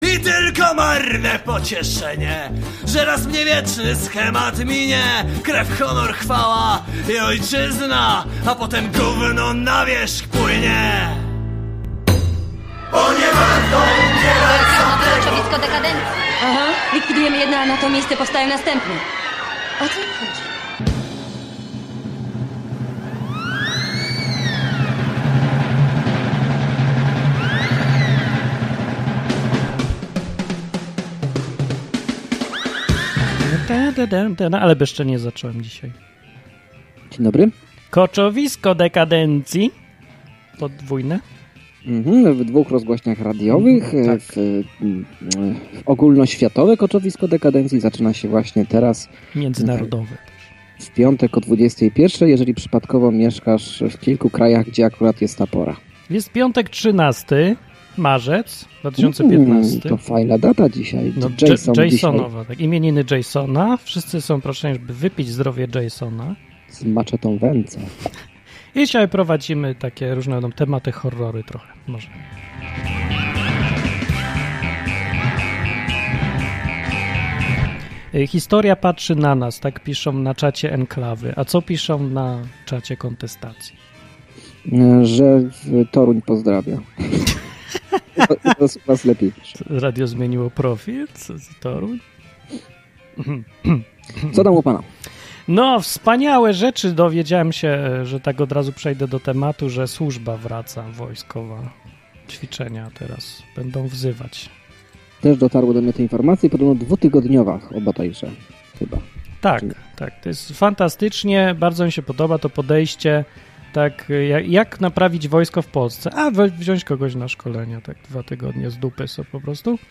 Koczowisko Dekandencji to dwu-radiowa audycja, w której od luzu, sarkazmu i ironii wióry lecą.